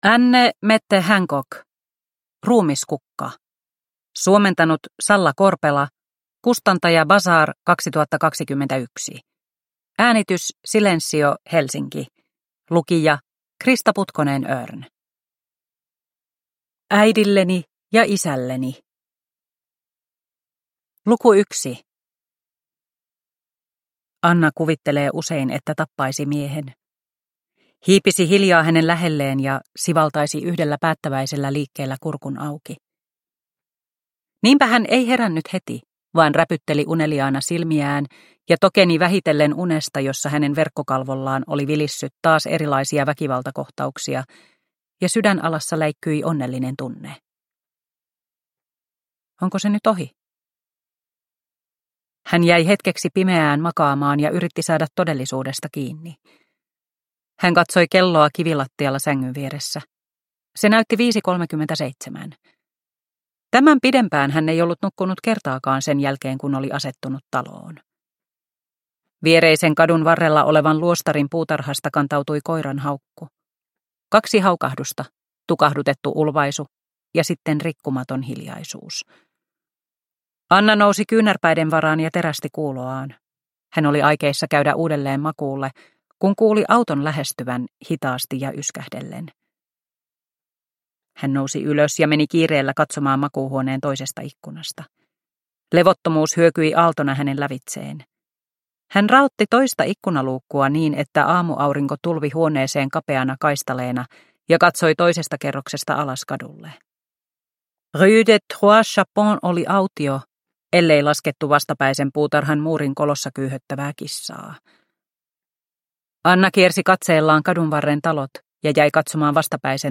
Ruumiskukka – Ljudbok – Laddas ner